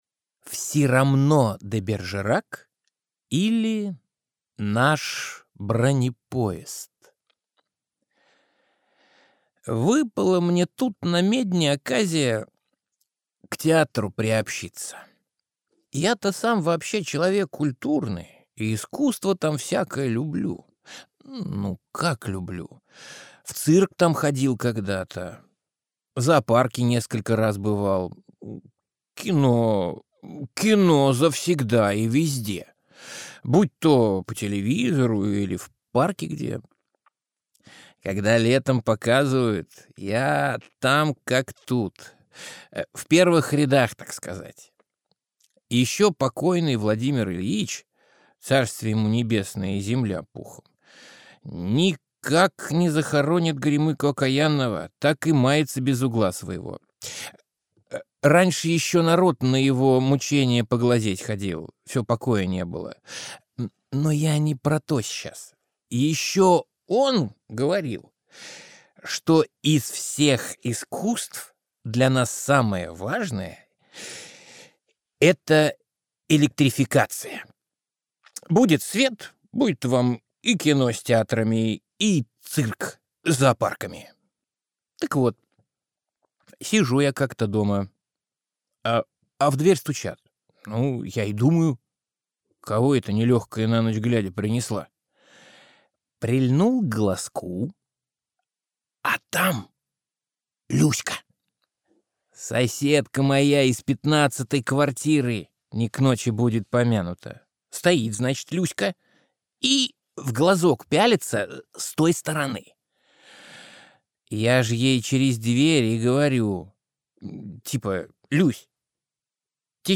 Аудиокнига Привет, Петрович | Библиотека аудиокниг